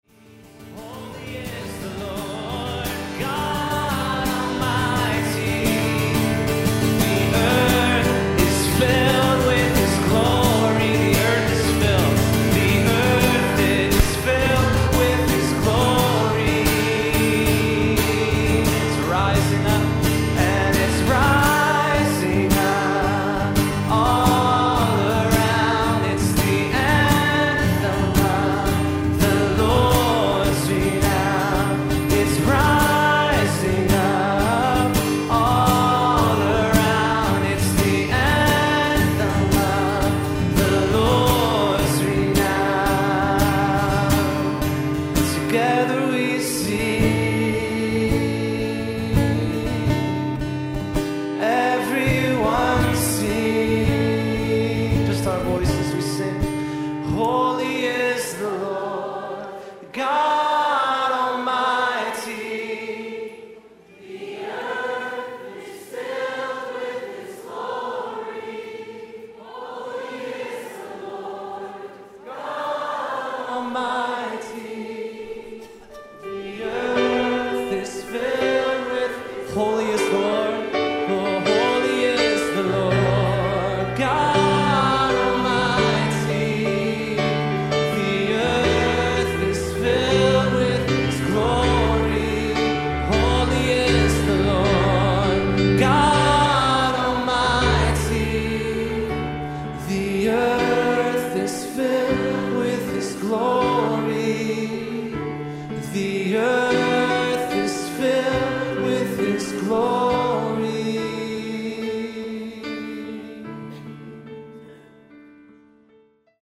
Oftentimes when I’m leading by myself, instead of setting up a second guitar, I’ll just make sure there’s a piano or a keyboard close by with a mic.
This happened a few months ago at a healing conference my church hosted. I was in the middle of the song “Holy is the Lord” by Chris Tomlin and Louie Giglio when my D string popped. I waited until after the bridge to move over to the piano.
First off, that was an excellent transition!
stringbreak.mp3